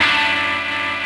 Remix10ChordF#m.wav